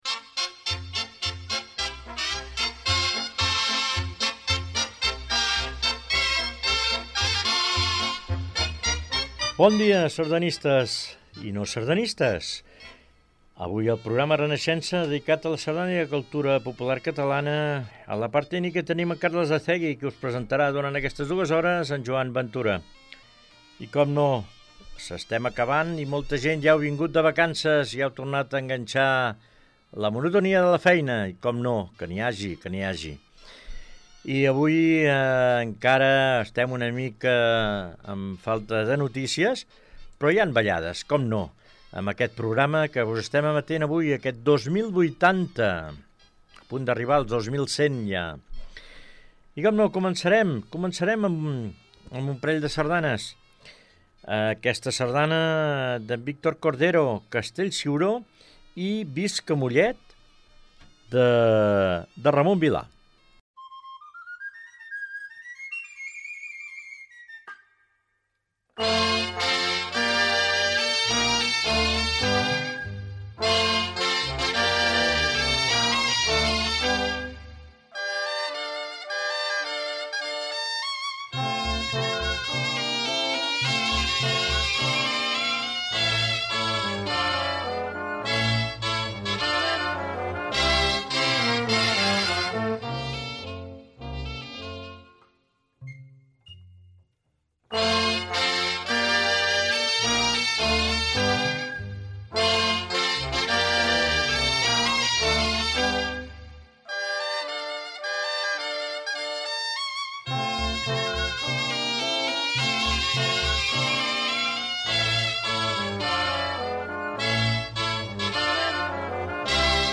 L'Agrupació Sardanista Amics de Castellar (ASAC) ofereix cada setmana un espai d'audicions, de participació telefònica, d'agenda i d'entrevistes sobre el món de la sardana a Renaixença.
En directe cada dissabte de 9 a 11 del matí, i en format reemissió els diumenges de 8 a 10 del matí.